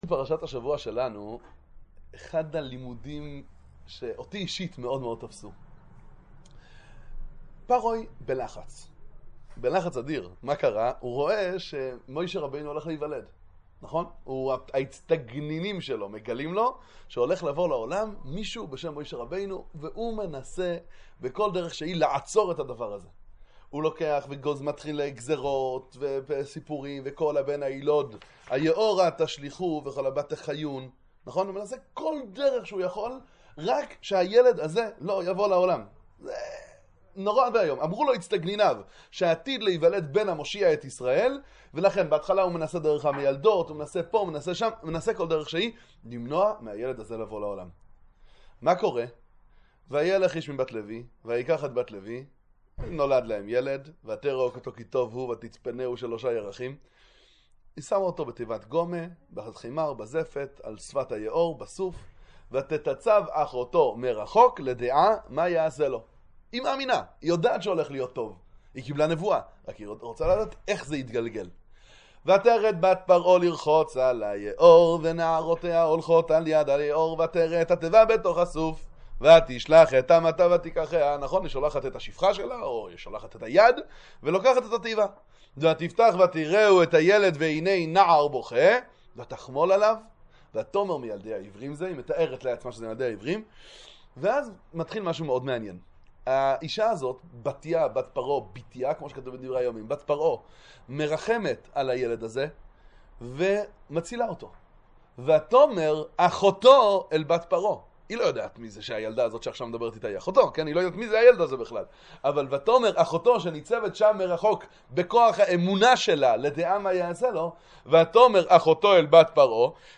מה מרויחים מהשתדלות? דבר תורה קצר לפרשת השבוע